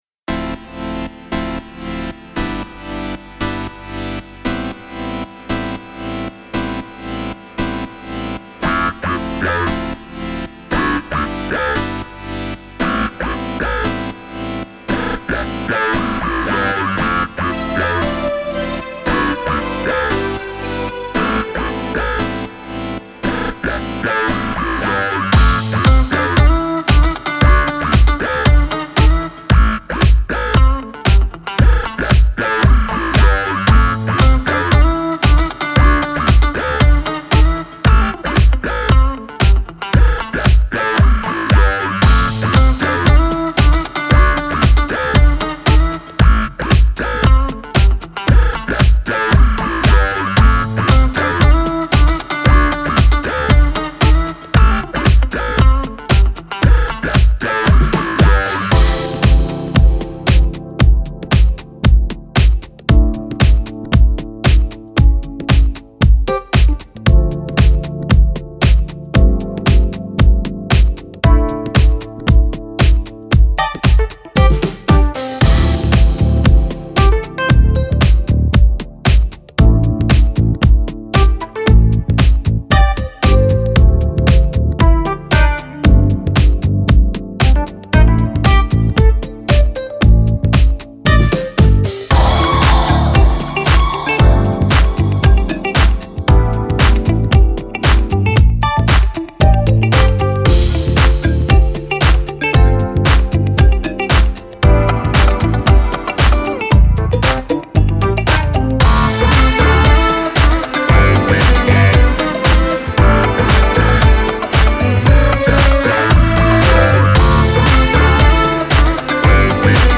instrumental edit